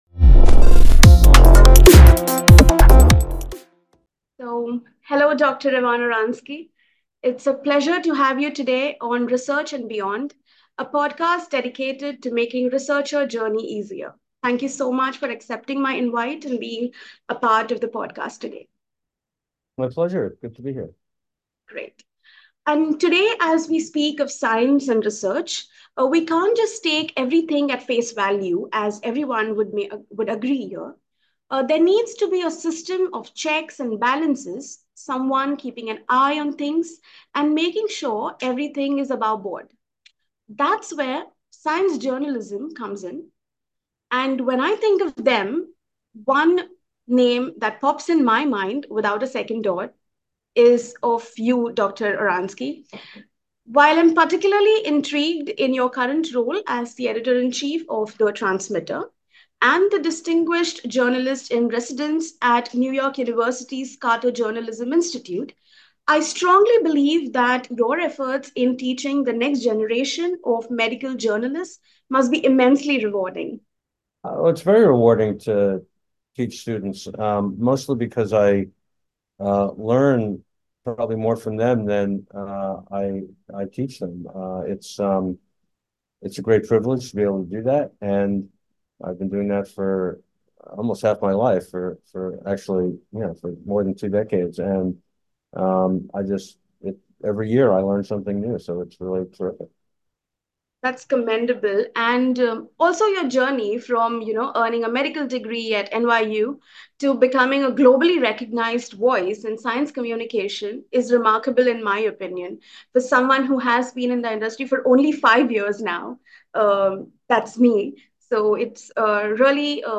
Retractions, Whistleblowers, and the Quest for Scientific Truth: A conversation with Ivan Oransky
By Enago Academy | Speaker: Dr. Ivan Oransky
In our podcast, Research and Beyond, join us for a captivating conversation with Ivan Oransky, co-founder of Retraction Watch, as we delve into the world of research integrity and the transformative impact of exposing misconduct.